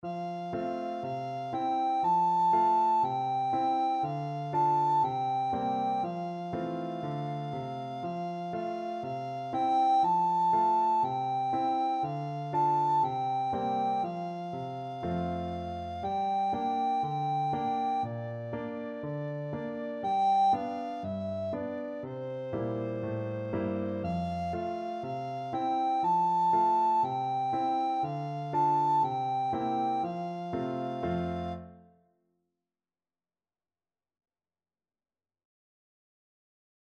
Traditional Trad. Au claire de la lune Soprano (Descant) Recorder version
Recorder
F major (Sounding Pitch) (View more F major Music for Recorder )
4/4 (View more 4/4 Music)
Allegro (View more music marked Allegro)
C6-A6
Traditional (View more Traditional Recorder Music)